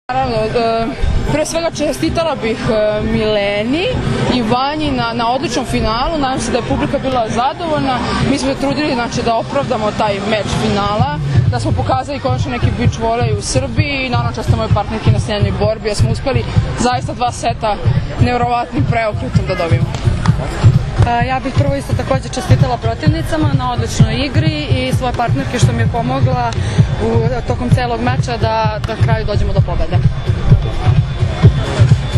IZJAVA SAŠE MILOŠEVIĆA, GRADONAČELNIKA UŽICA